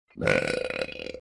the pig burping sound effect Meme Sound Effect
the pig burping sound effect.mp3